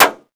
Index of /server/sound/vj_impact_metal/bullet_metal
metalsolid6.wav